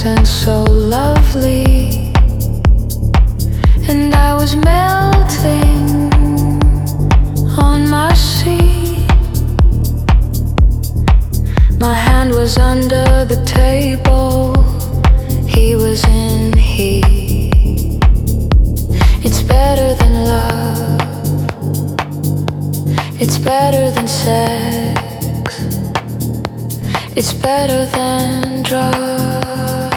House Dance
Жанр: Танцевальные / Хаус / Украинские